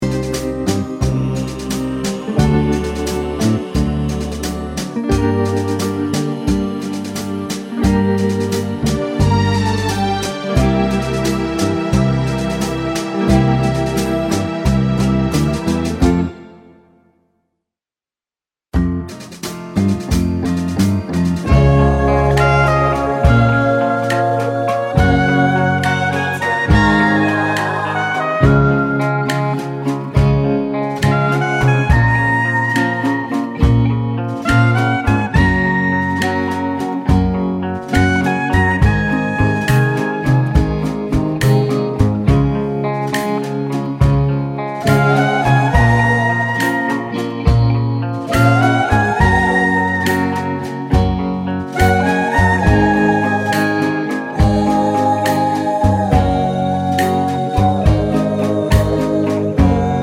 One Semitone Higher Pop (1960s) 11:23 Buy £1.50